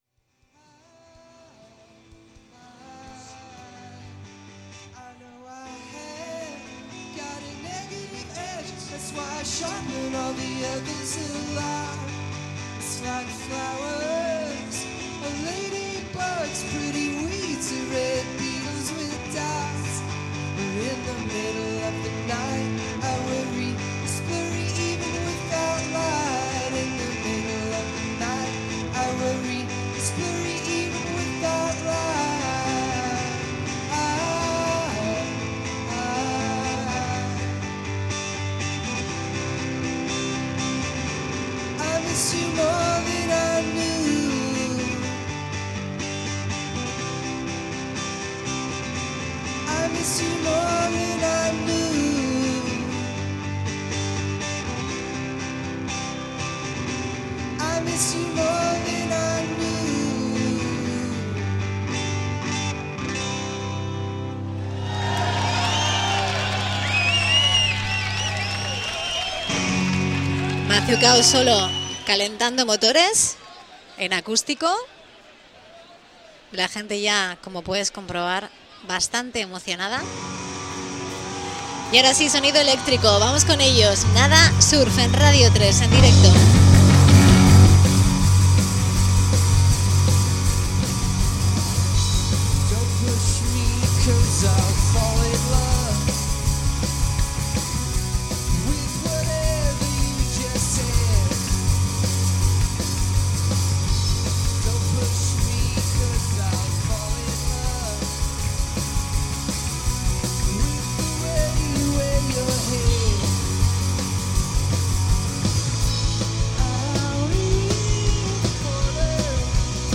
Live from Europe.